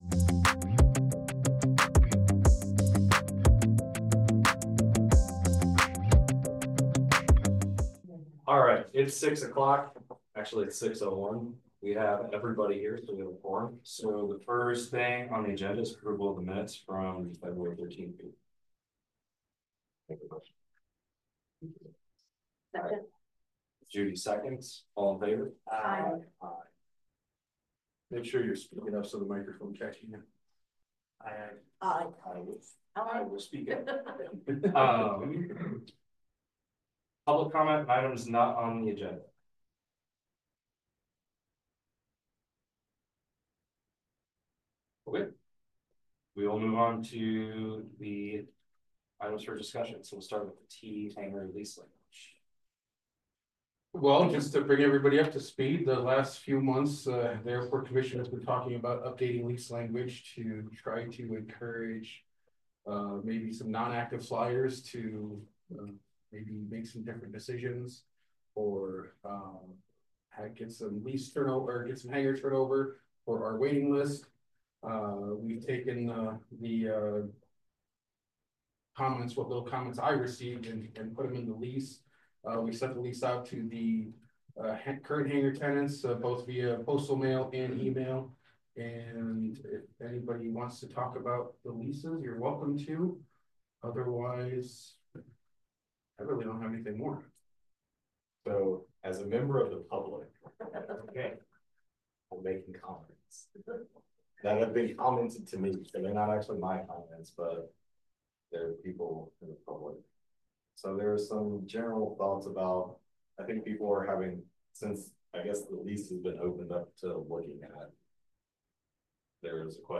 Regular monthly meeting of the City of Iowa City's Airport Commission.